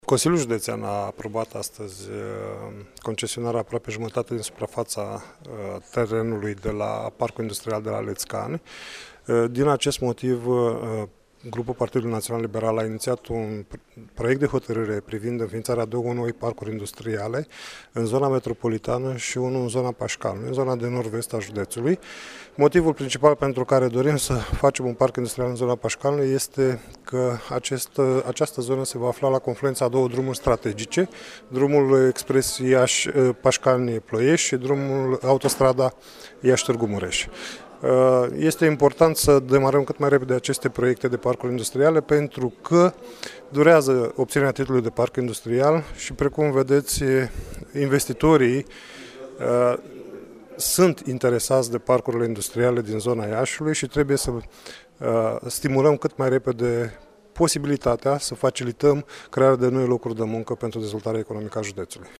La rândul său liderul consilierilor județeni PNL Romeo Vatra a ținut să sublinieze că: